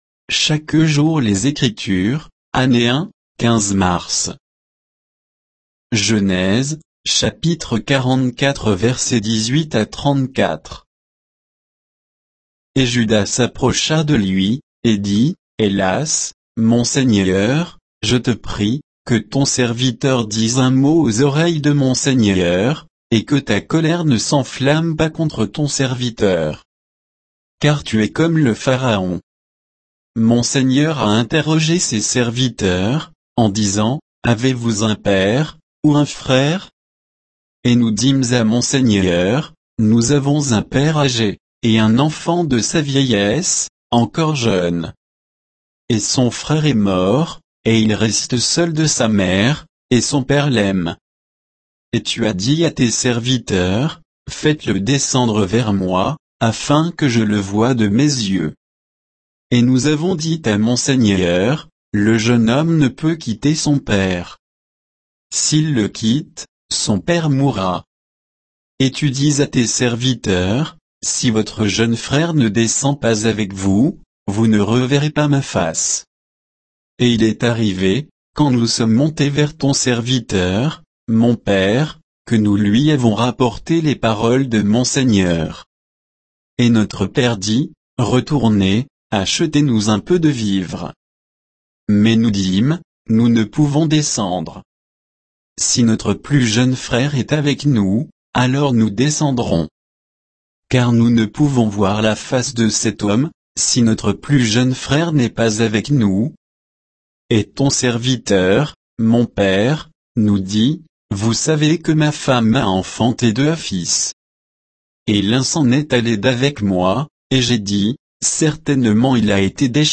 Méditation quoditienne de Chaque jour les Écritures sur Genèse 44, 18 à 34